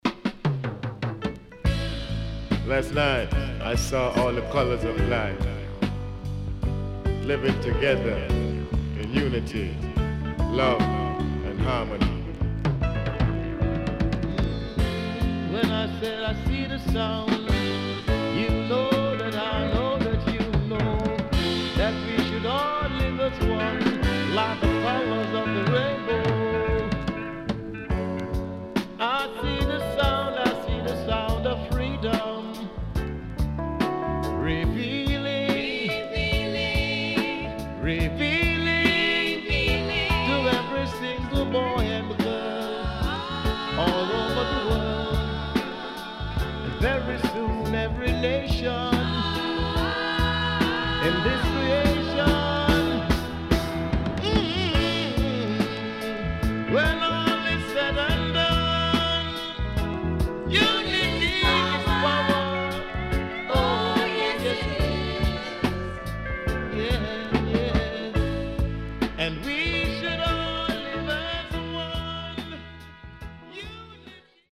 より洗練されたソウルフルな音使いに、メッセージ性の強い渋Roots Rock名盤
SIDE A:少しチリノイズ入りますが良好です。